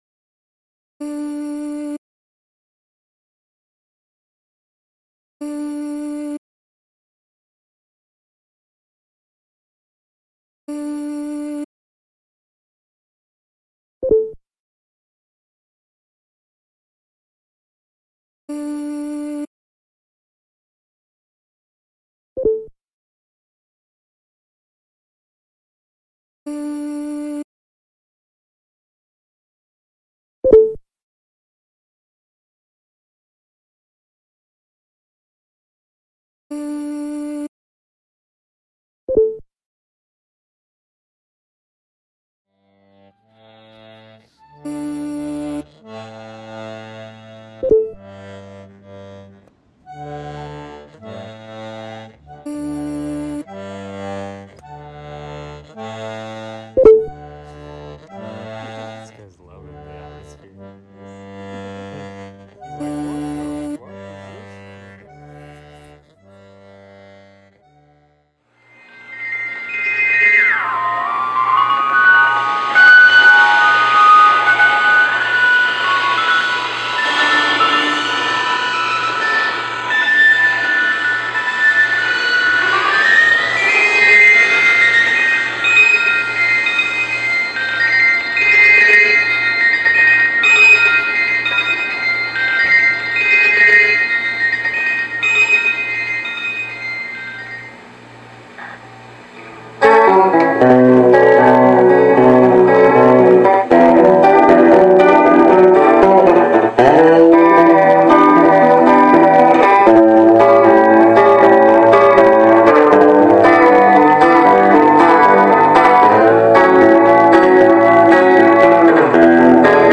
Electronic Pop
Electronic Experimental Indie Live Performance Synth